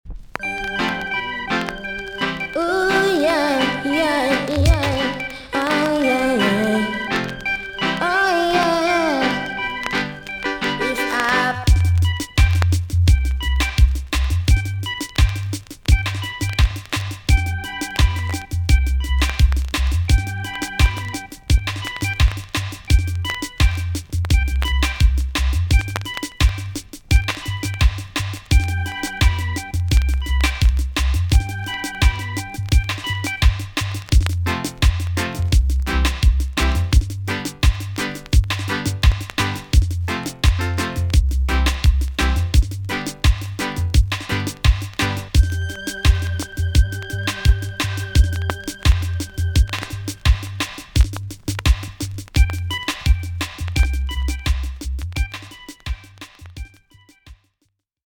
TOP >80'S 90'S DANCEHALL
B.SIDE Version
EX-~VG+ 少し軽いチリノイズがありますが良好です。